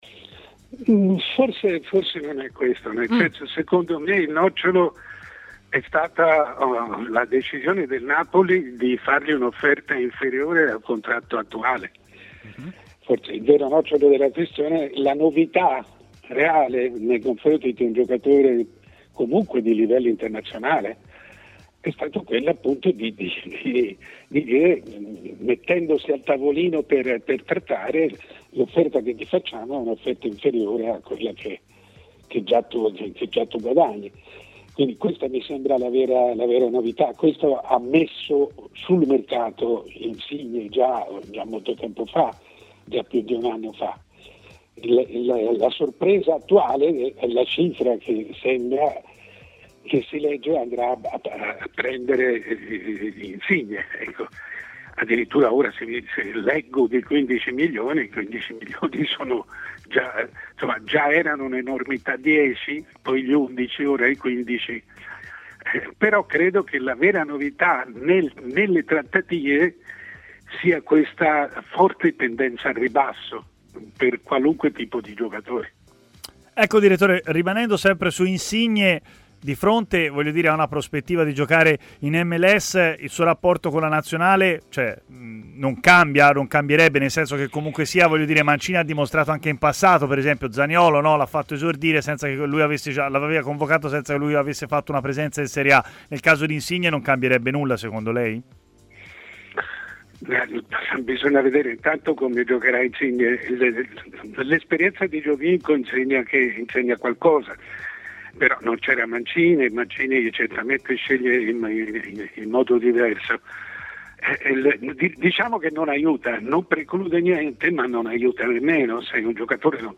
L'opinionista Mario Sconcerti, decano del giornalismo sportivo italiano, ha commentato ai microfoni di Tmw Radio l'addio quasi ufficiale di Insigne al Napoli, pronto a sbarcare a Toronto: "Il nocciolo è stata la decisione del Napoli di fargli una proposta inferiore all'ingaggio attuale.